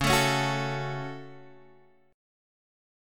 C#sus4#5 chord {x 4 4 2 2 2} chord